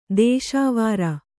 ♪ dēśāv āra